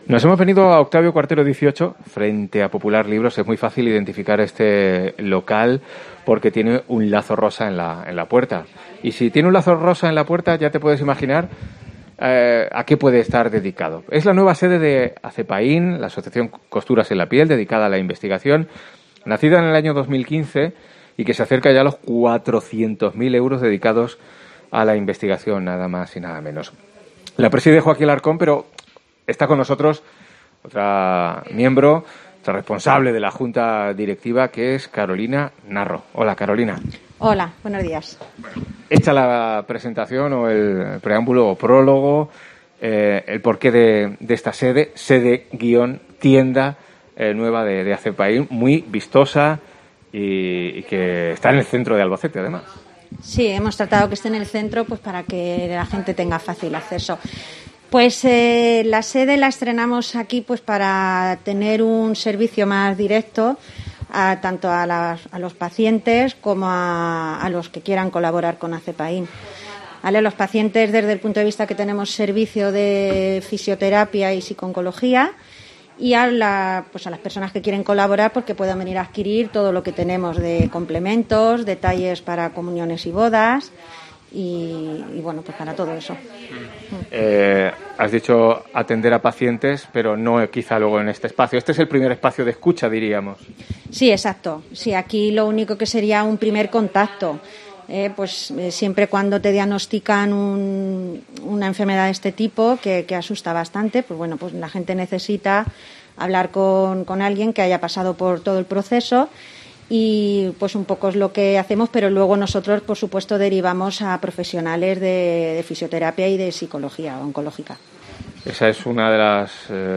Mediodía COPE desde la nueva sede de Acepain